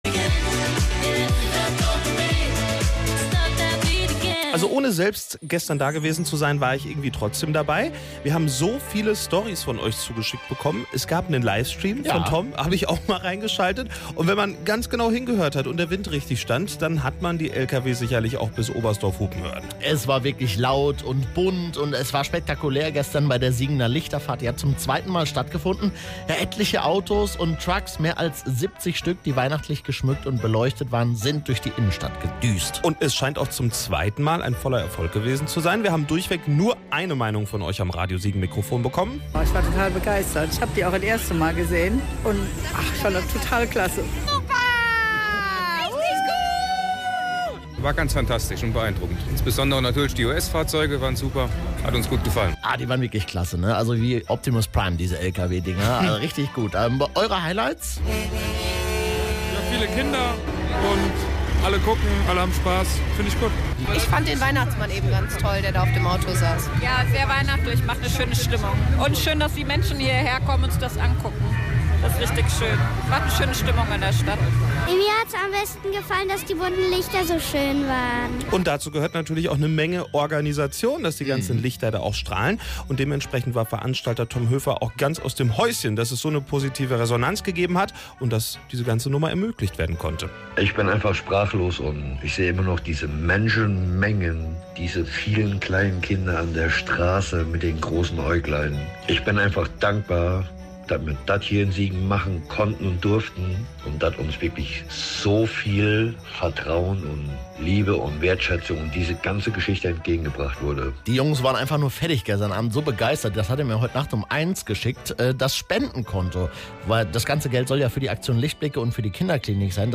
Radio Siegen war mit einem Livestream und mit einem Mikro dabei.